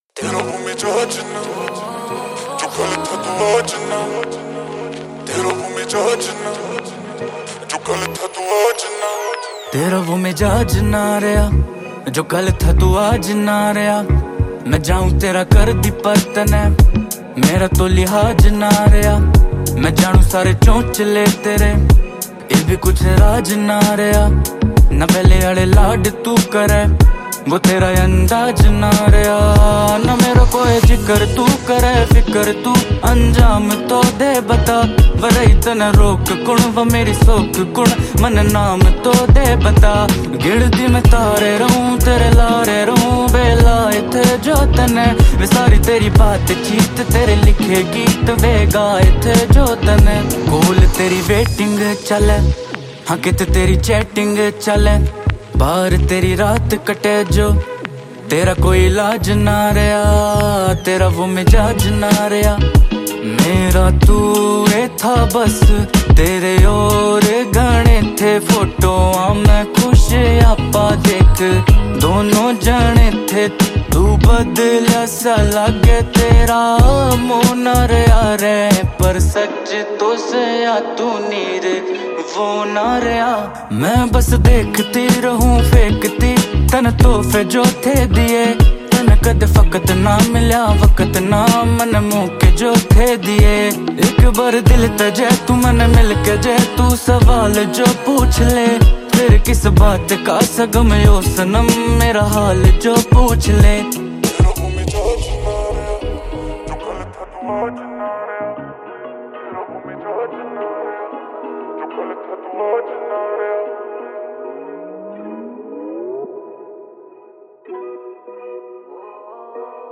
Releted Files Of New Haryanvi Song 2025